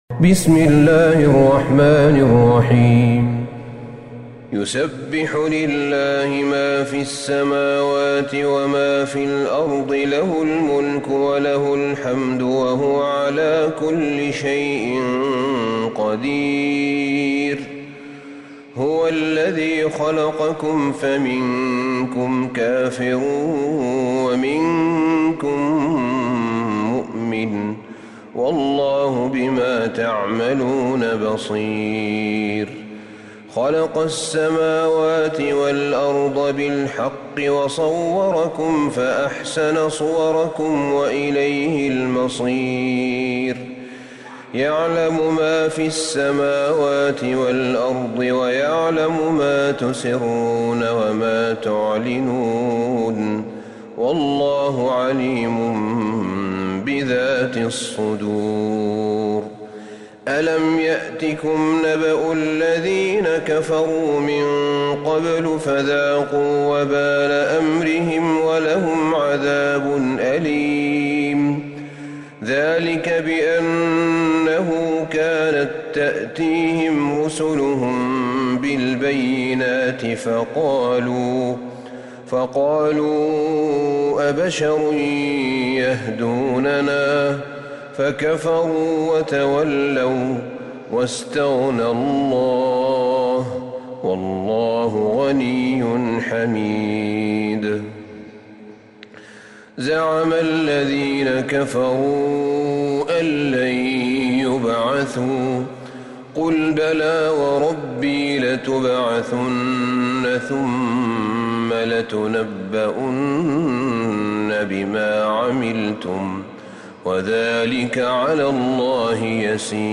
سورة التغابن Surat At-Taghabun > مصحف الشيخ أحمد بن طالب بن حميد من الحرم النبوي > المصحف - تلاوات الحرمين